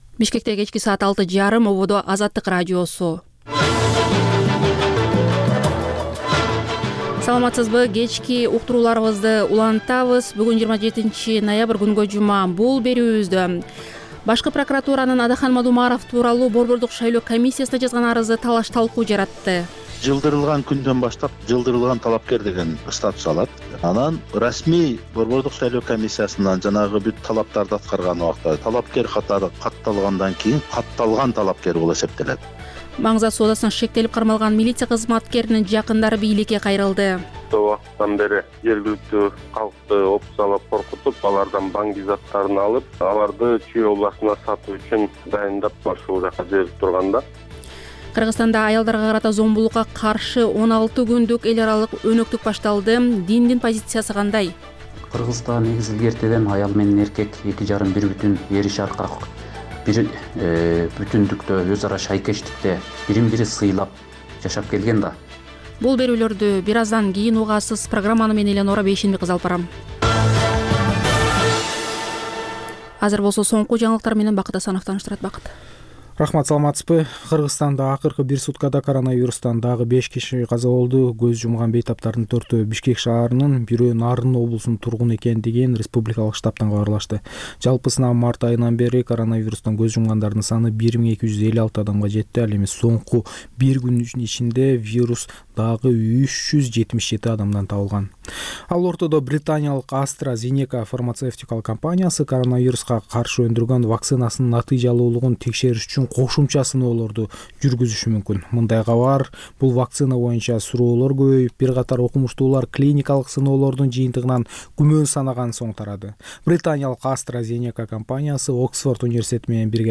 Бул үналгы берүү ар күнү Бишкек убакыты боюнча саат 18:30дан 19:00гө чейин обого түз чыгат.